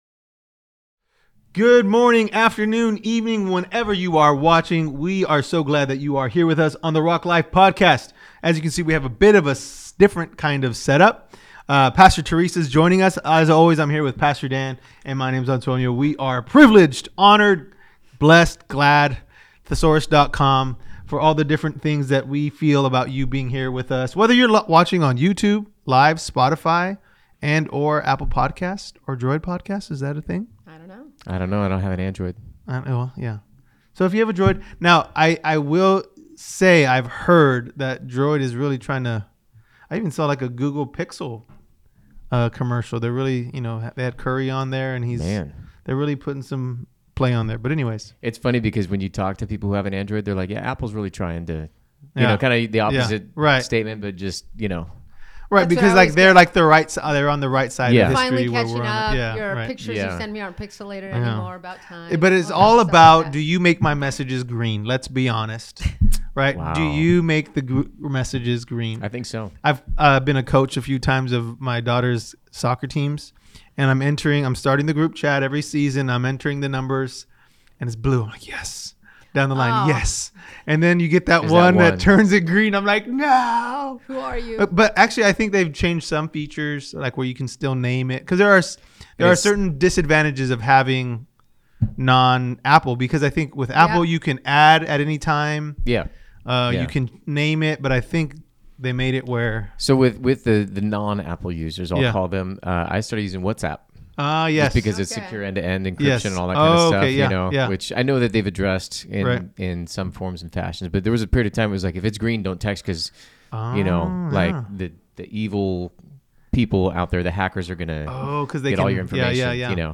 Rock Life Podcast: Episode 87 | Pastors Q&A Panel Part 1 | The Rock Church